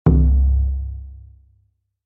surdo-5.mp3